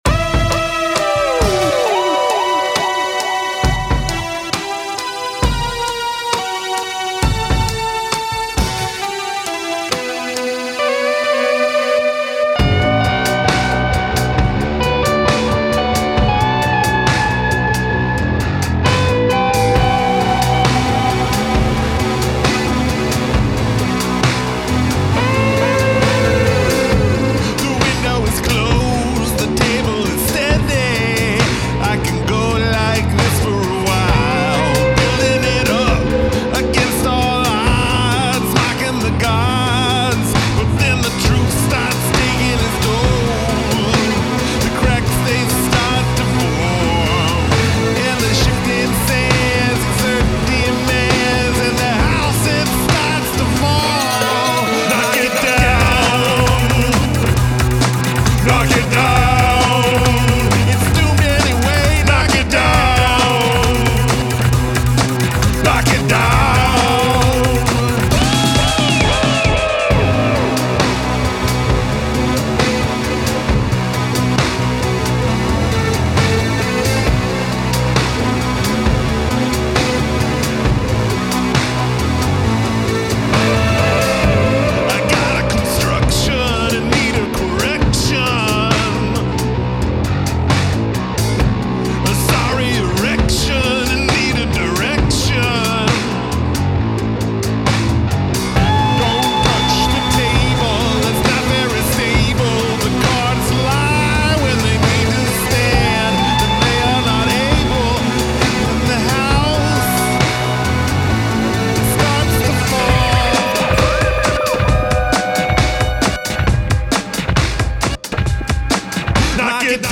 very cool sounds, a little messy.
Love the echoey stuff.
The brooding atmosphere in this one is awesome.